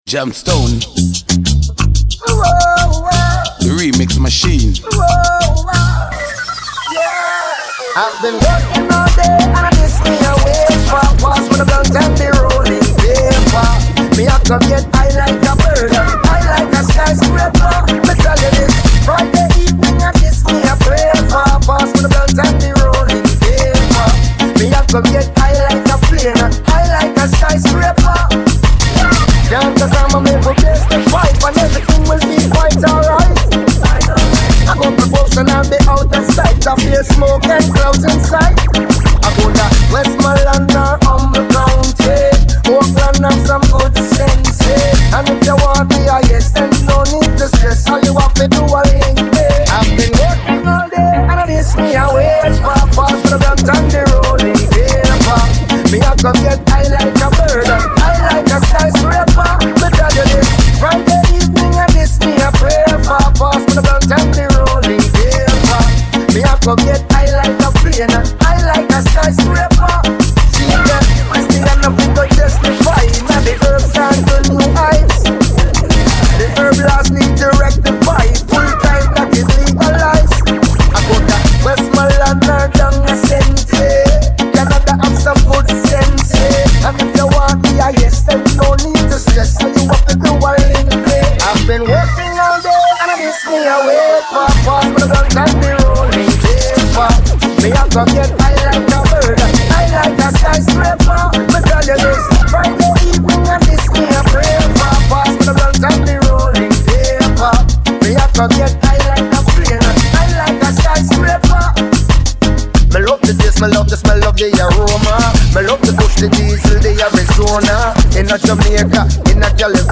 (remastered)